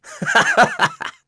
Crow-Vox_Happy1.wav